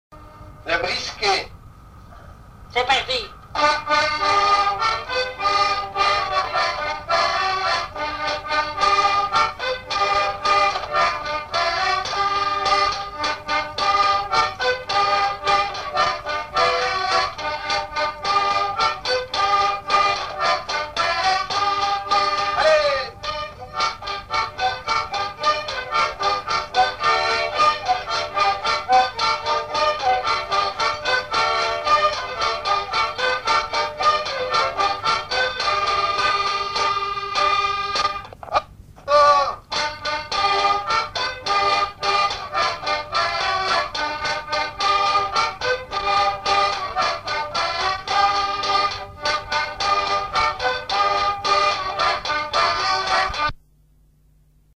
danse : brisquet
enregistrements du Répertoire du violoneux
Pièce musicale inédite